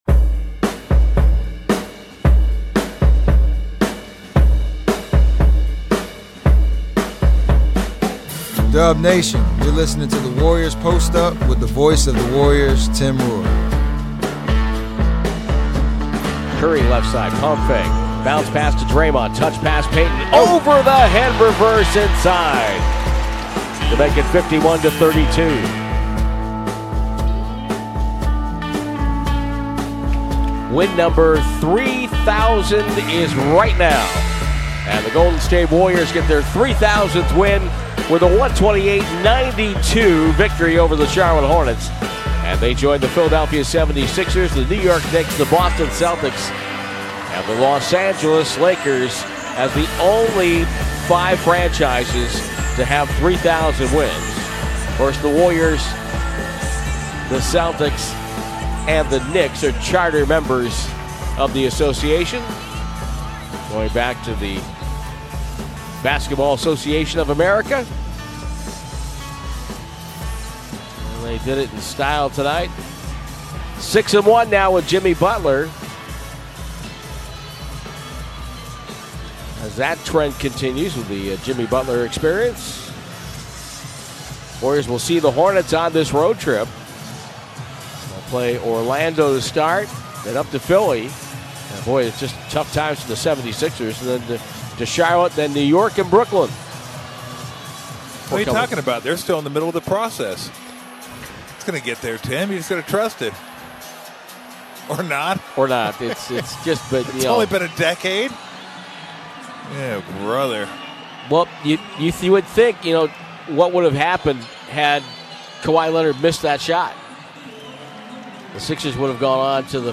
Official Golden State Warriors shows with interviews and analysis direct from the team, focusing on topics from in and around the NBA